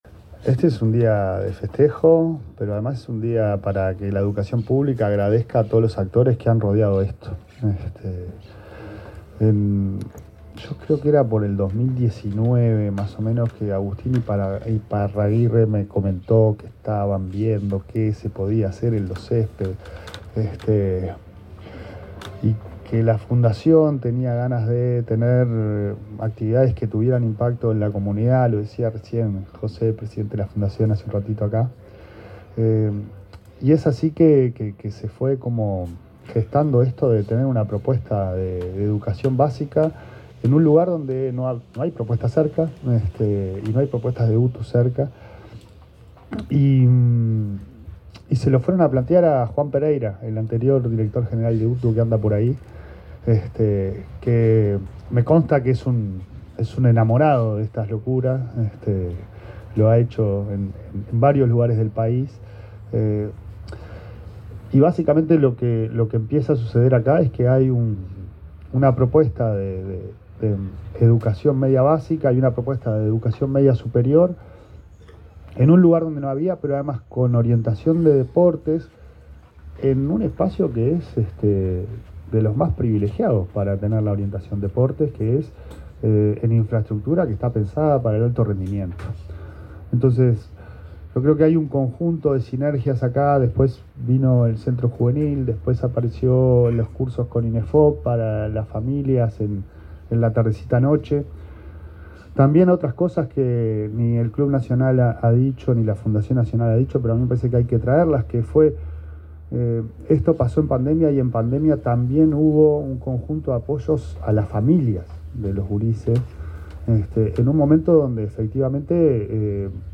Palabras del presidente de ANEP, Pablo Caggiani
Palabras del presidente de ANEP, Pablo Caggiani 11/09/2025 Compartir Facebook X Copiar enlace WhatsApp LinkedIn En la celebración del 5.° aniversario de la UTU de la Ciudad Deportiva Los Céspedes del Club Nacional de Football, el titular de la Administración Nacional de Educación Pública, Pablo Caggiani, se expresó en el acto protocolar.